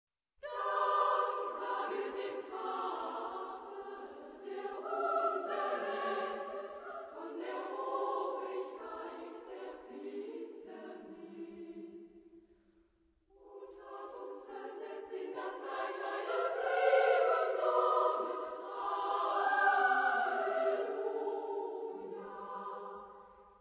Genre-Stil-Form: geistlich ; romantisch
Charakter des Stückes: melodiös ; fröhlich
Chorgattung: SSA  (3 Frauenchor Stimmen )
Tonart(en): G-Dur